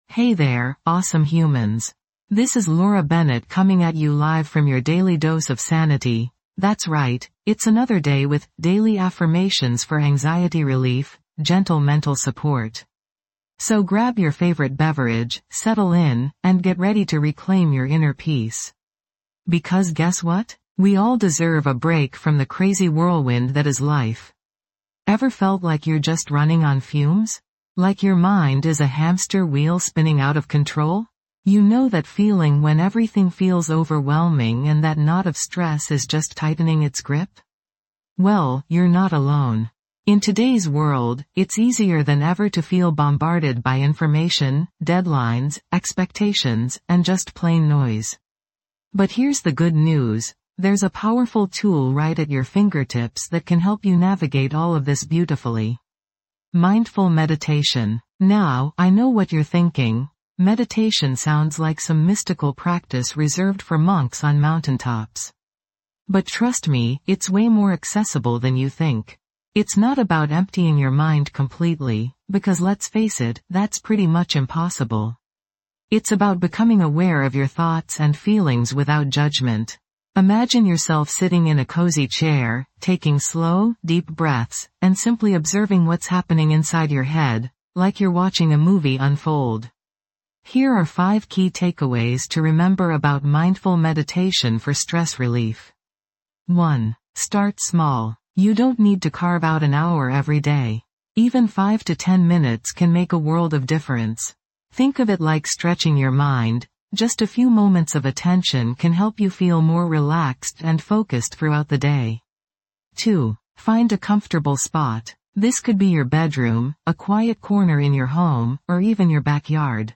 Episode Description:.Dive into a transformative meditation journey designed specifically for stress relief in this weeks episode of Daily Affirmations for Anxiety Relief Gentle Mental Support. Unwind as our soothing voice guides you through a series of mindful meditations, promoting relaxation and reducing anxiety.